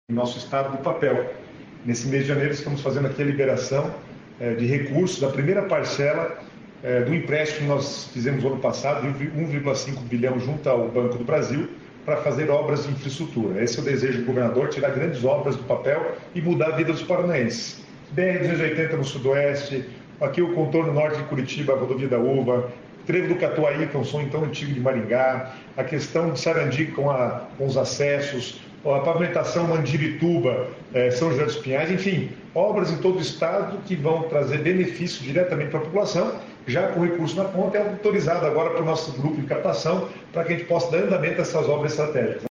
Sonora do secretário do Planejamento, Guto Silva, sobre a liberação de recursos para obras de infraestrutura